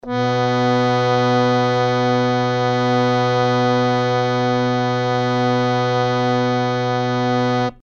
harmonium
B2.mp3